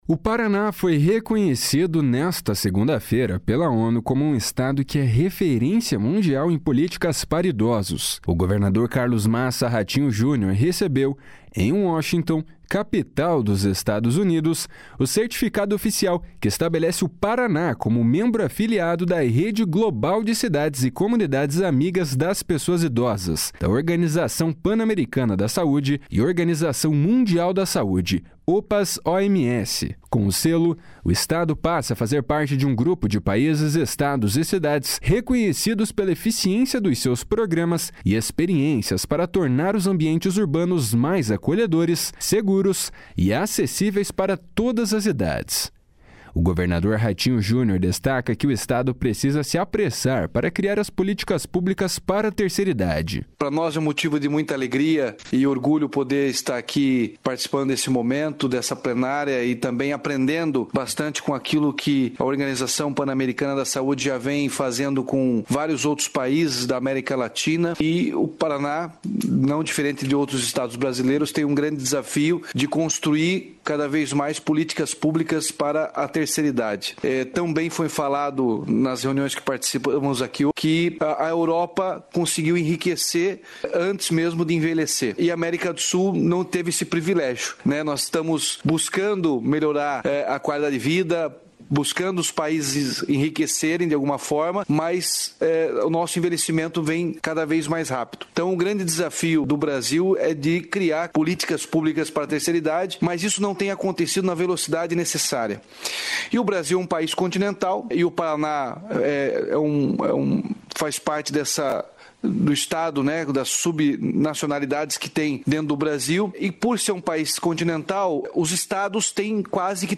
O governador Ratinho Junior destaca que o Estado precisa se apressar para criar as políticas públicas para a terceira idade.
A secretária de Mulher, Pessoa Idosa e Igualdade Racial, Leandre Dal Ponte, destaca as ações do Estado.